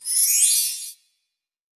9BELLTREE.wav